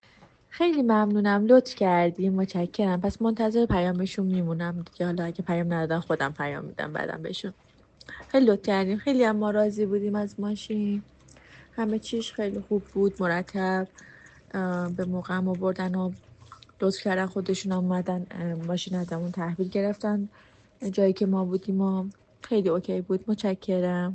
تجربه اجاره خودرو در دبی را از زبان مشتریان کاراپلاس بشنوید